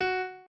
piano6_15.ogg